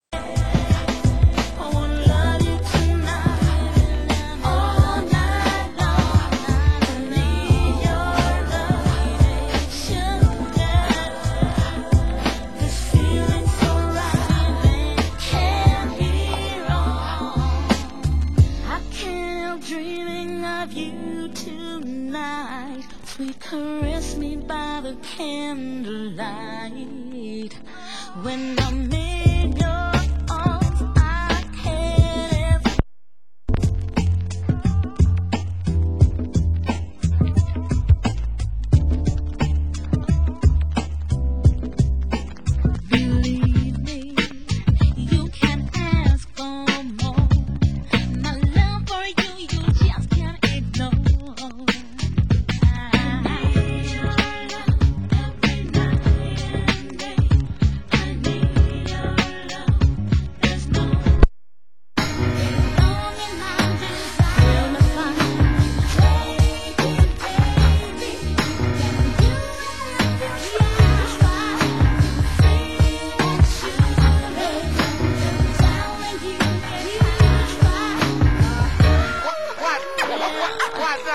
Genre: Down Tempo